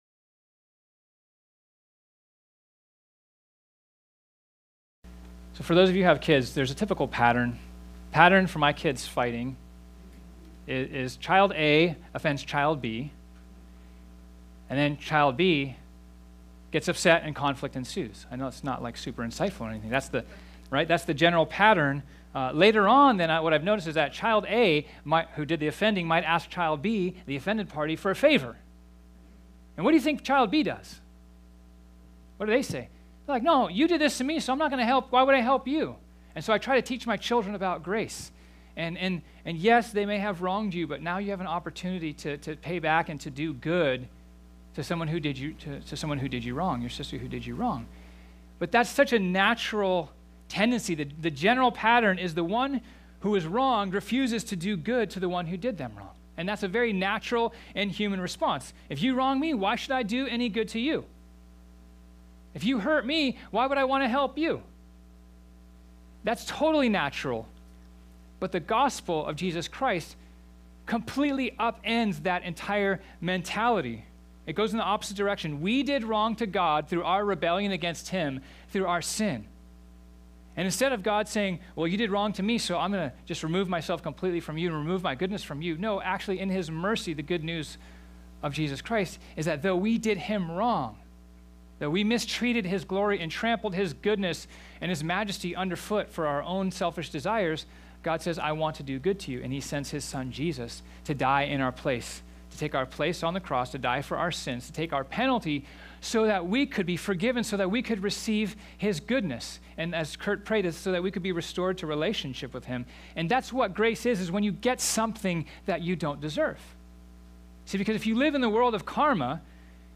This sermon was originally preached on Sunday, March 11, 2018.